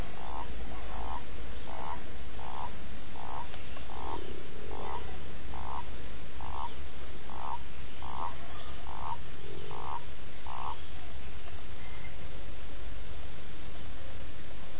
Bez problemu można odróżnić wysoki śpiew ropuch i niski, basowy żab trawnych.
godowy rechot samca ropuchy